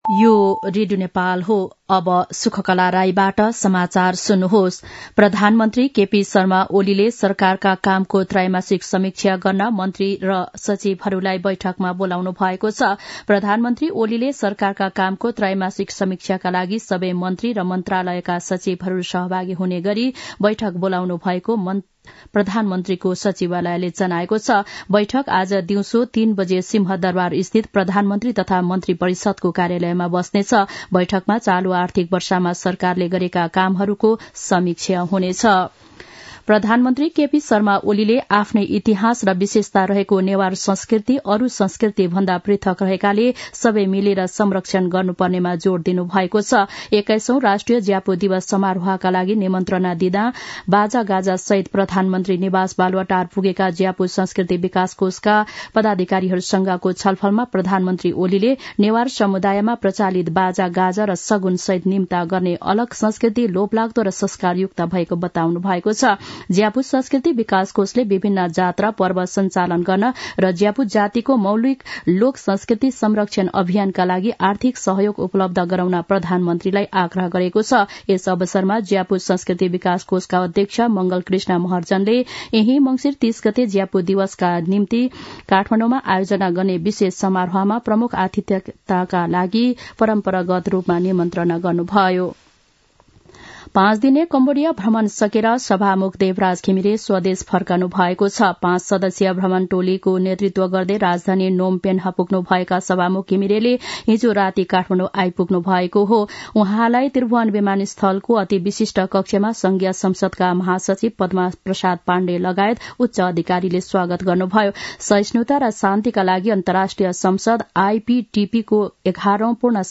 An online outlet of Nepal's national radio broadcaster
दिउँसो १ बजेको नेपाली समाचार : १३ मंसिर , २०८१
1-pm-nepali-news-1-9.mp3